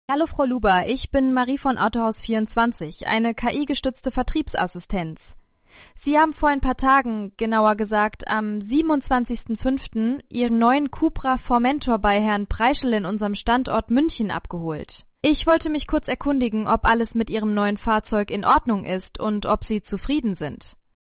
o Format: WAV, mono, 8 kHz, µ-law (pcm_mulaw), very small file
• Source files are generated via ElevenLabs (MP3), then converted with ffmpeg, e.g.: